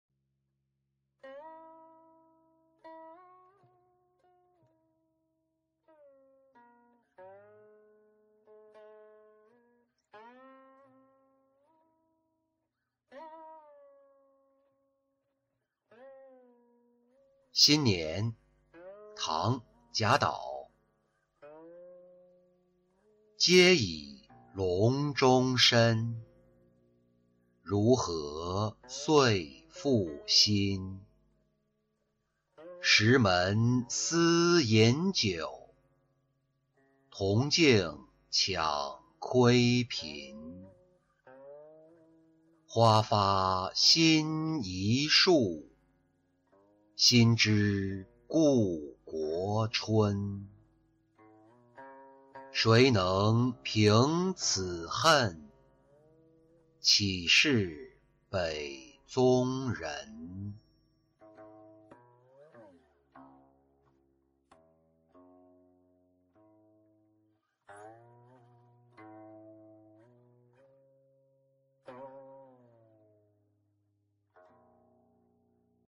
新年-音频朗读